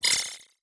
Hi Tech Alert 7.wav